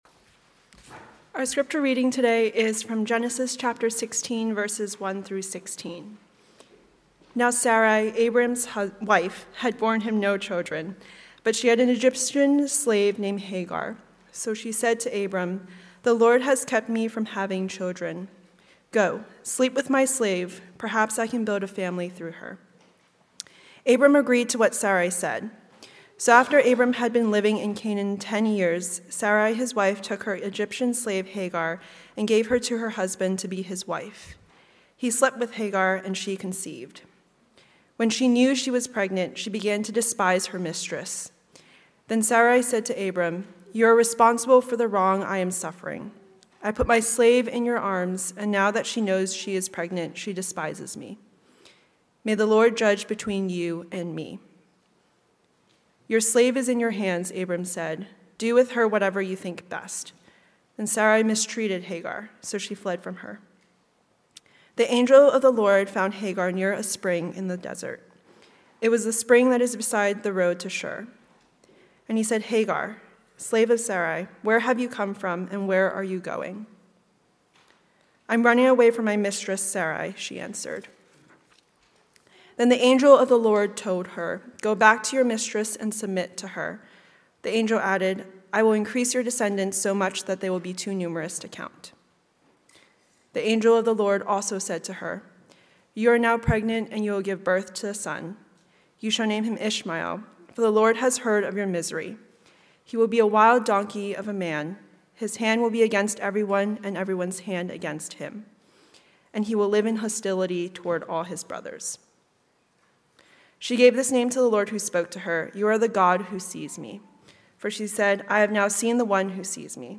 Sermons – North Shore Community Baptist Church